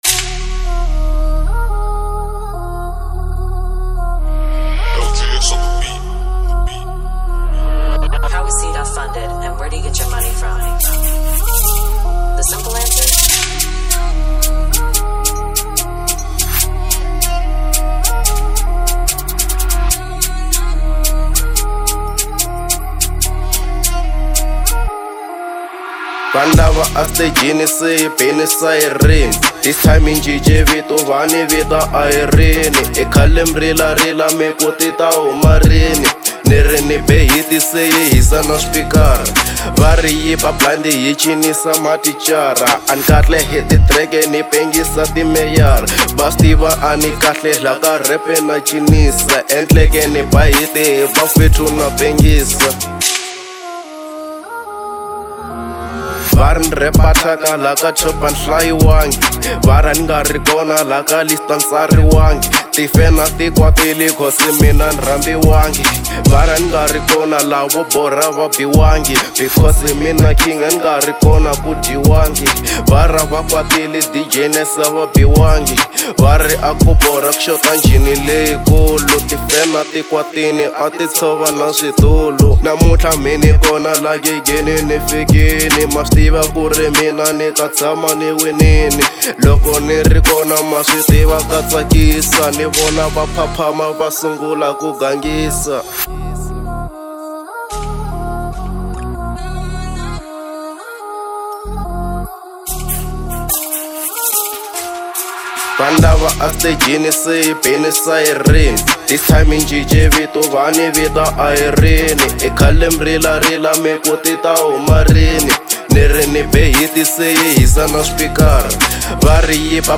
03:08 Genre : Hip Hop Size